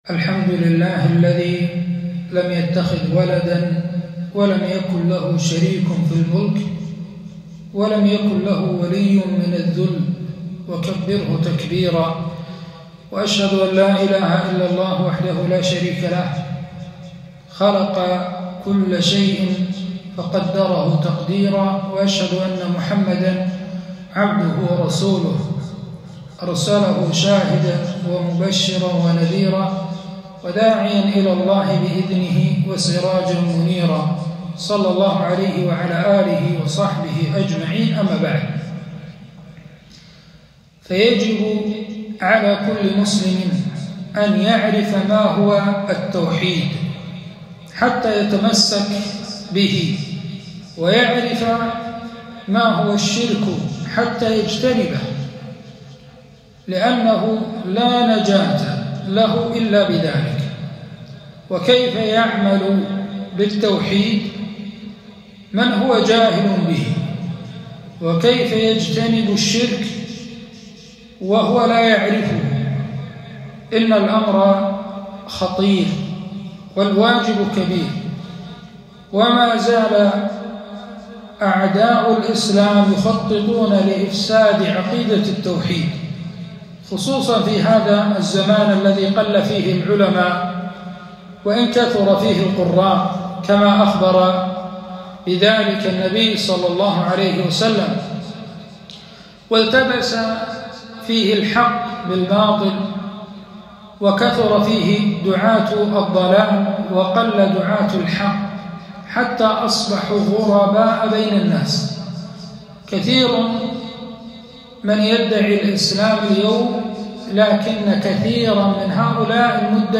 كلمة - التحذير من الشرك ووجوب الخوف منه